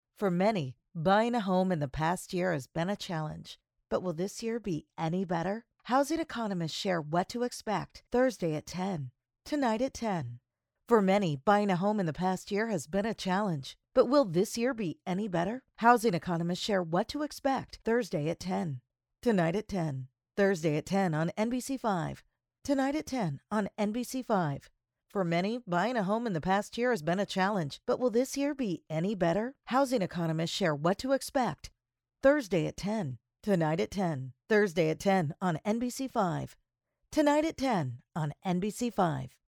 Female
trustworthy, confident, warm, wry, sarcastic, informational, authoritative, fun, news, promo, voice of god, real, smart, engaging, conversational, source-connect
Commercial.mp3
Microphone: Sennheiser MKH 416